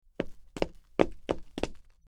Footsteps On Concrete 03
Footsteps_on_concrete_03.mp3